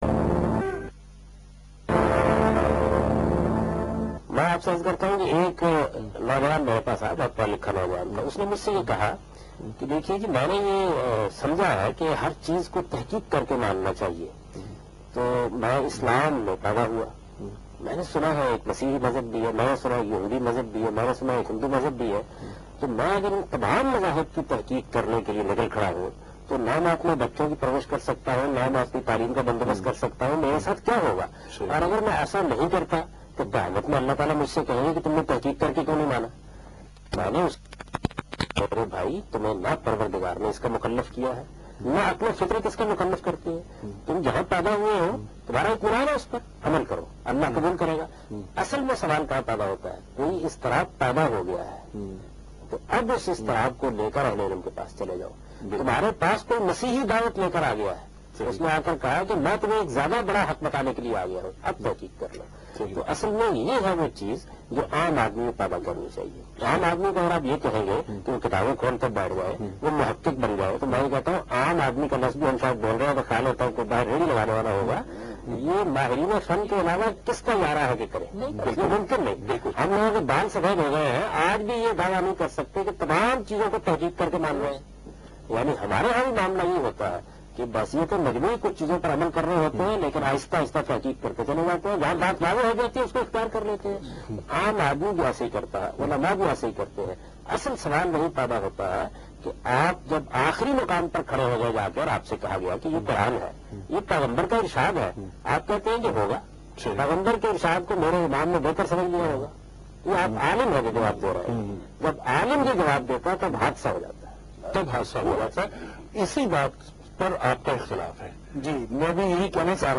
Category: TV Programs / Geo Tv / Alif /
What is Taqleed (Imitation)? Is Taqleed is obligatory ? This Program was telecast on Geo TV and host of this Program is Khursheed Nadeem.